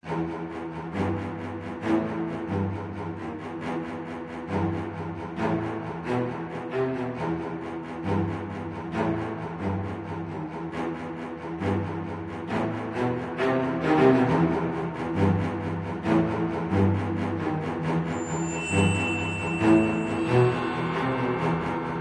Боевая музыка для подводной схватки акулы и человека